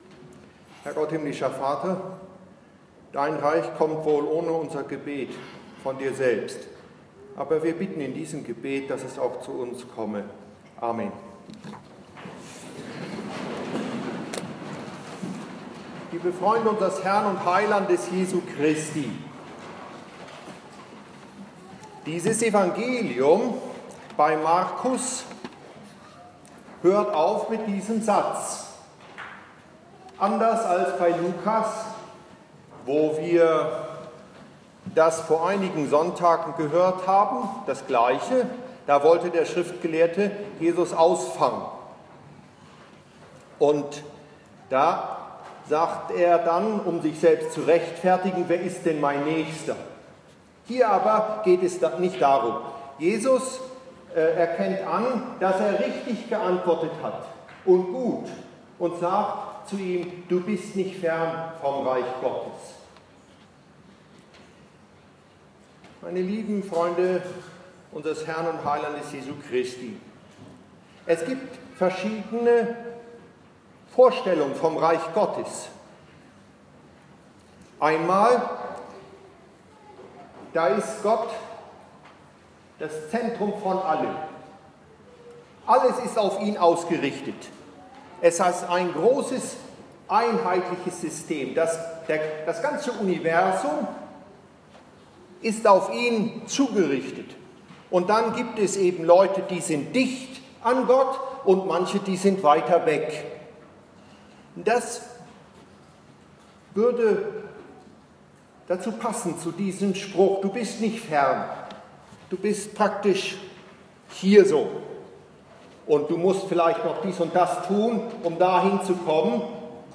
18. Sonntag nach Trinitatis in St.Paul